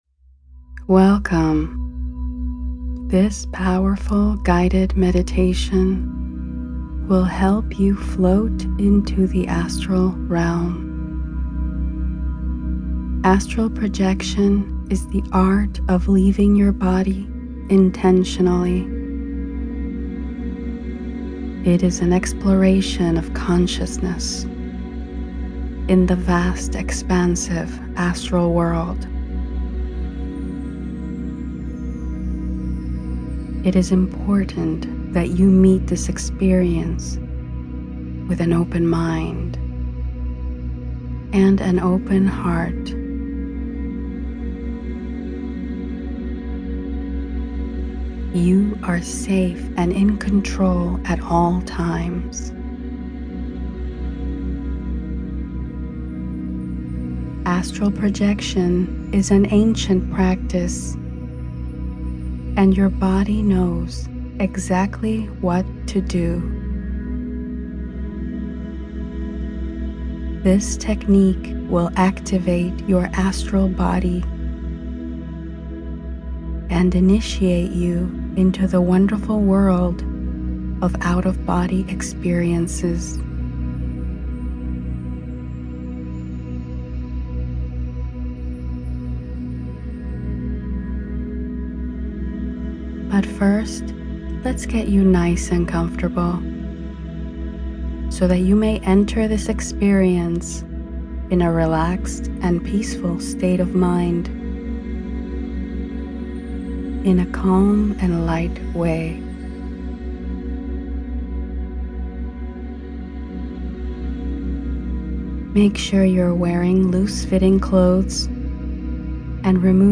The Floating Balloon Technique This astral projection meditation guide will help you with the powerful «floating balloon» technique. We recommend using headphones / earplugs for the best experience, since the meditation is embedded with 4.5Hz theta binaural beats.